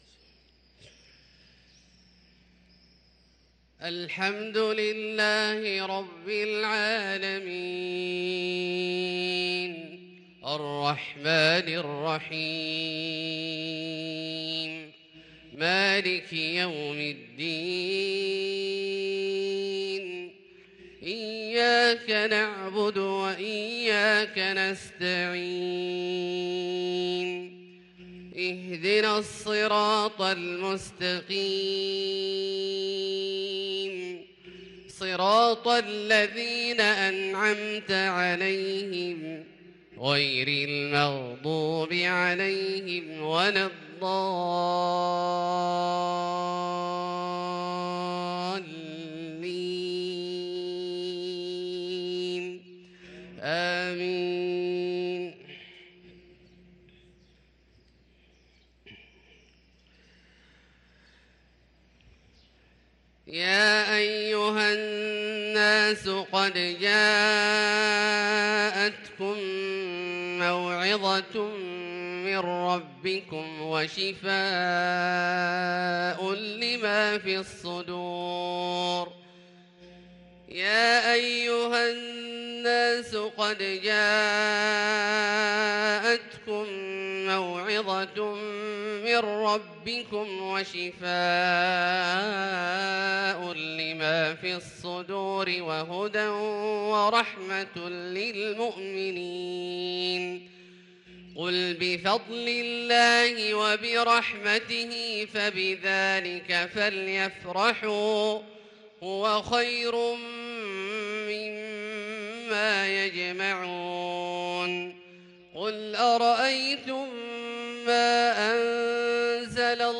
صلاة الفجر للقارئ عبدالله الجهني 20 ربيع الأول 1444 هـ
تِلَاوَات الْحَرَمَيْن .